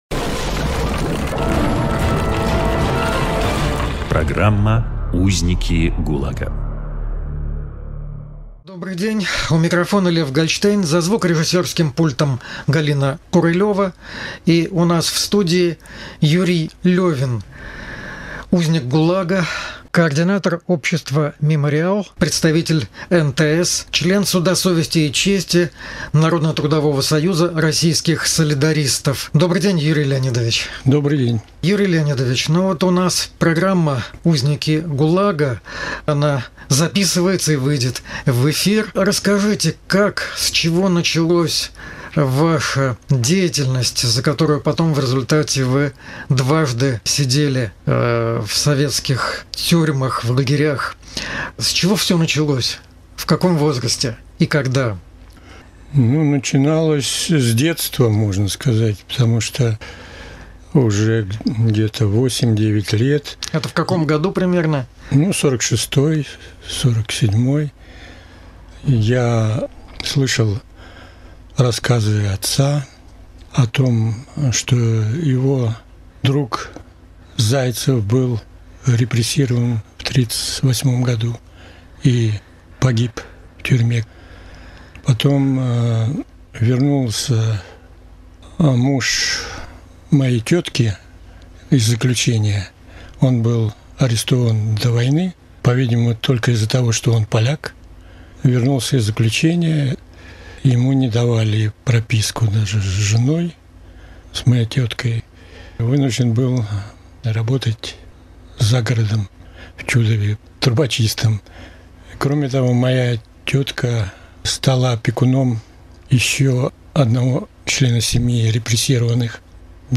Запись эфира из архивов «Эха Петербурга»